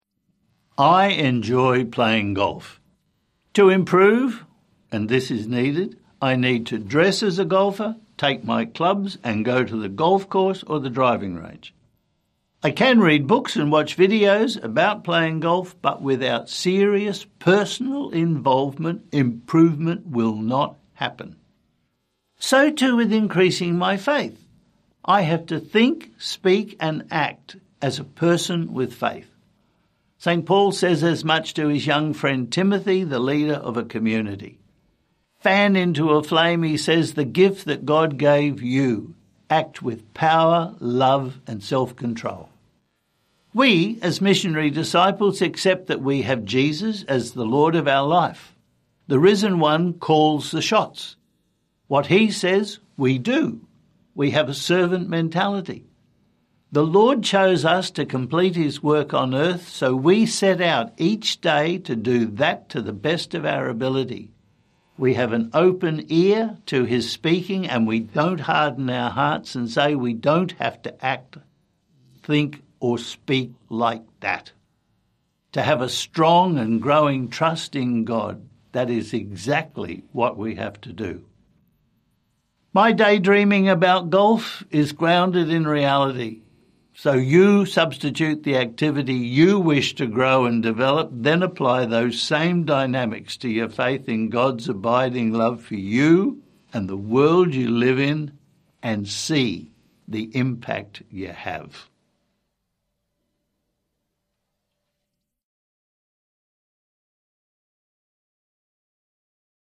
Archdiocese of Brisbane Twenty-Seventh Sunday in Ordinary Time - Two-Minute Homily